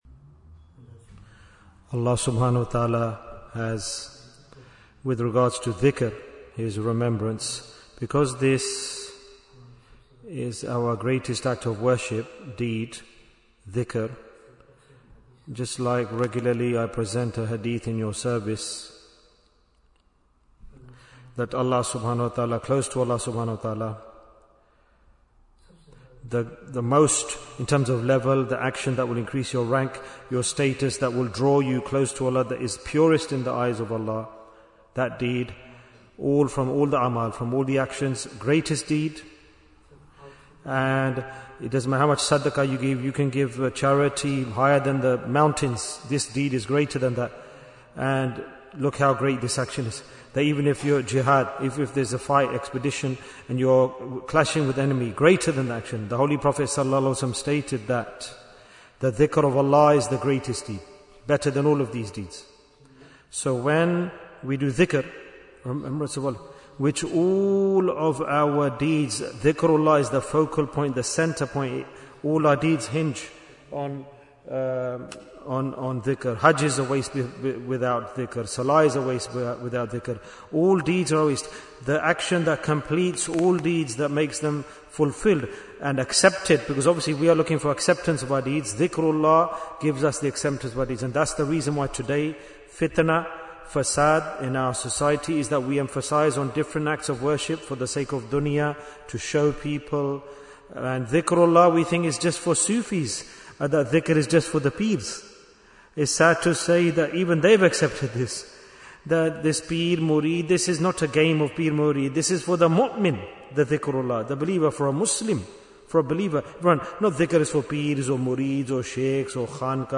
Bayan After Dhuhr in Manchester Bayan, 52 minutes12th April, 2026